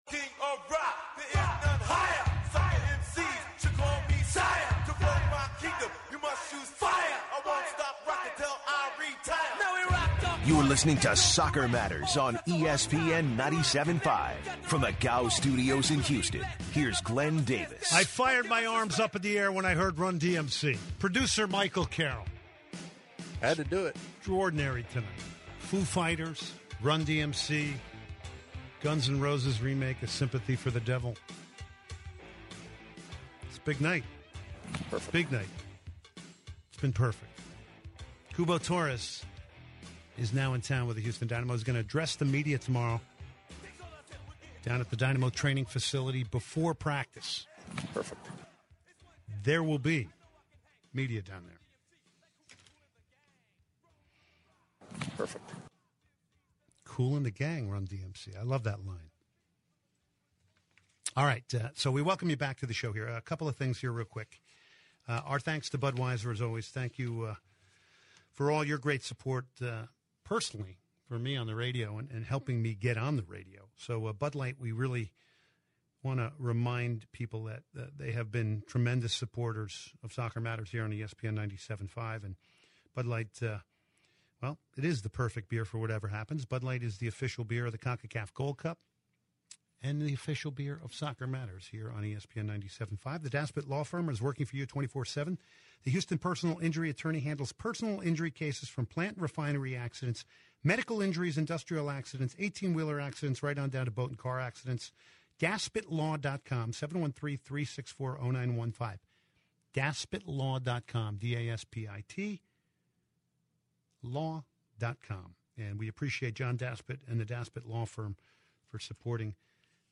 The show ends with an interview from Fox analyst Warren Barton to discuss the Gold Cup.